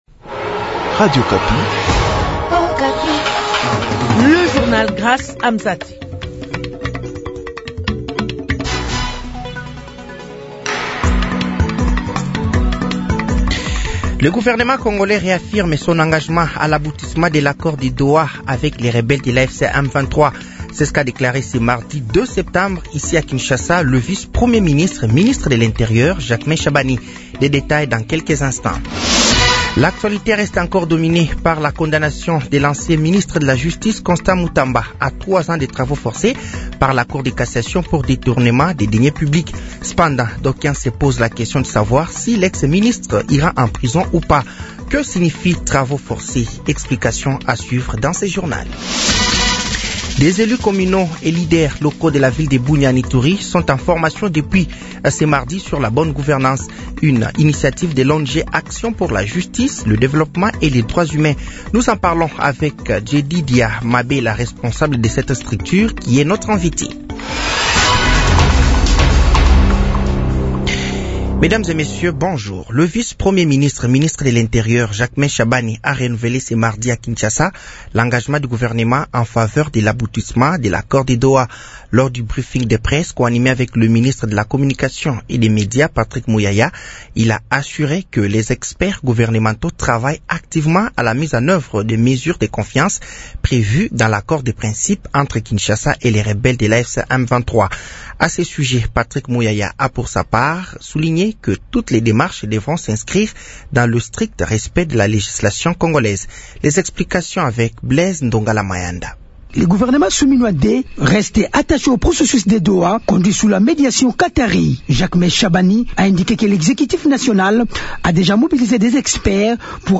Journal français de 15h de ce mercredi 03 septembre 2025